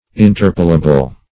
Search Result for " interpolable" : The Collaborative International Dictionary of English v.0.48: Interpolable \In*ter"po*la*ble\, a. That may be interpolated; suitable to be interpolated.